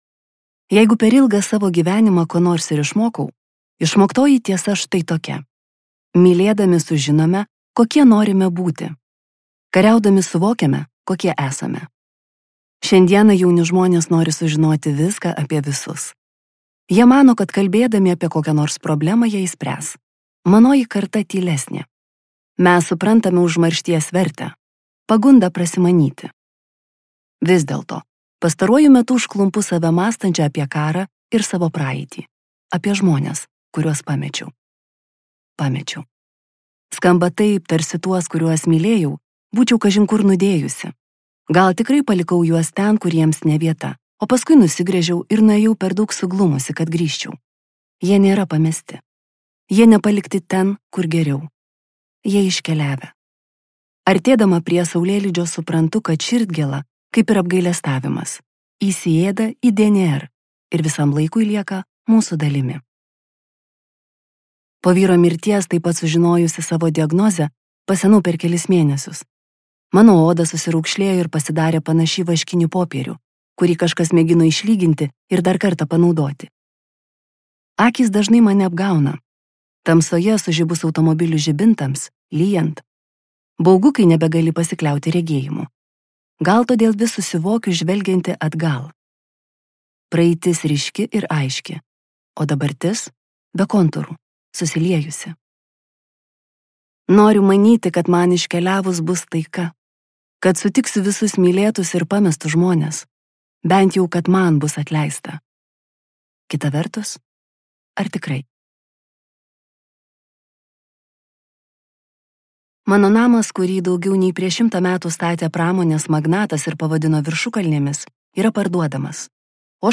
Kristin Hannah audioknyga